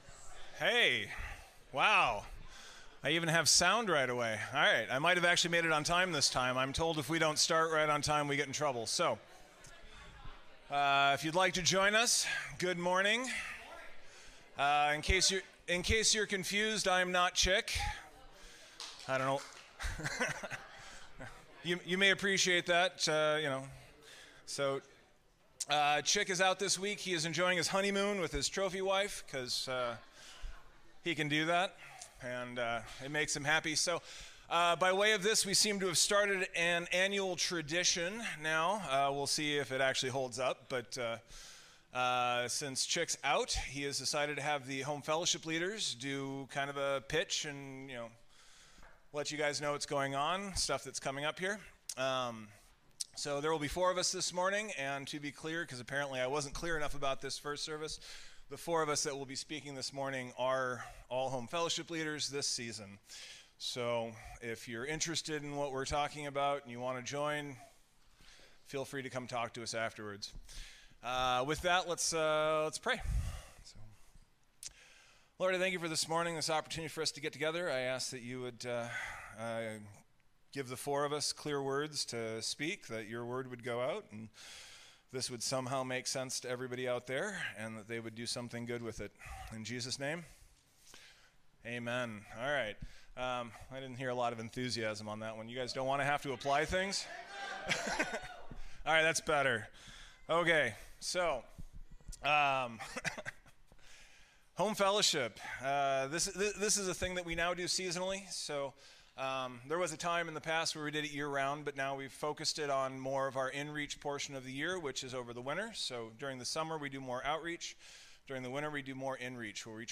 A message from the series "Sunday Morning." The Home Fellowship leaders explain what Home Fellowships are, why we have them, and why you should join one.